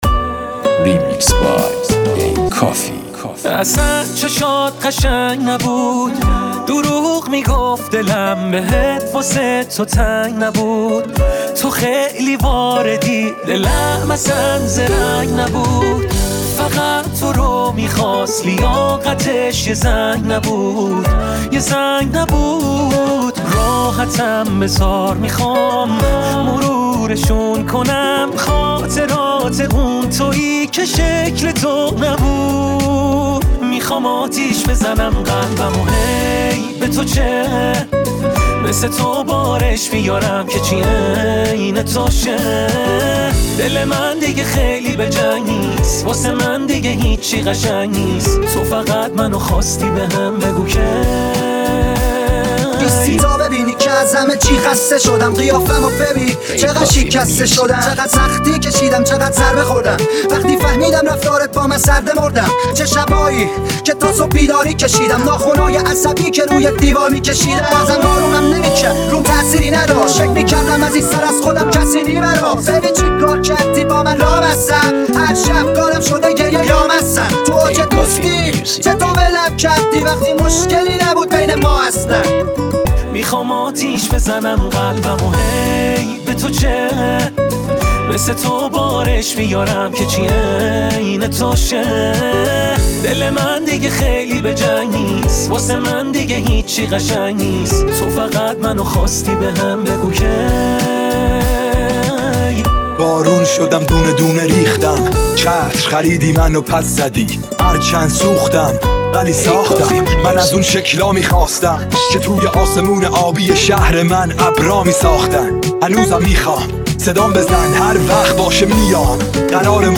ژانر: رپ و پاپ